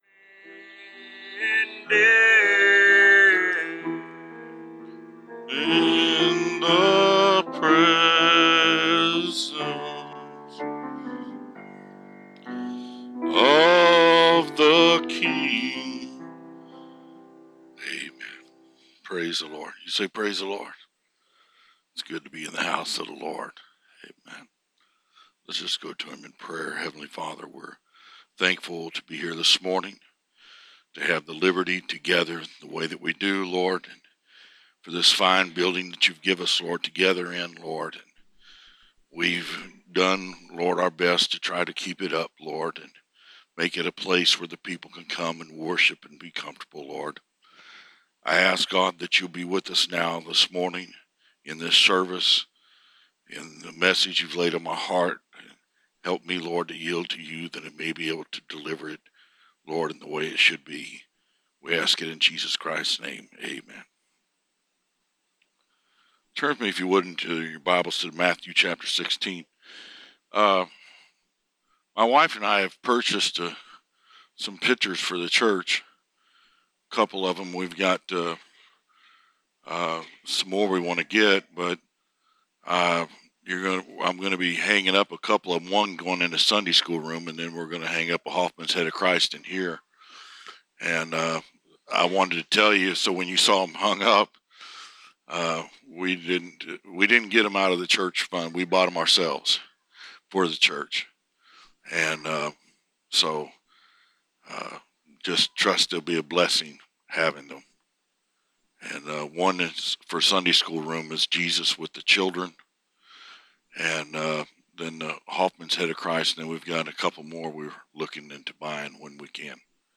Preached March 31, 2019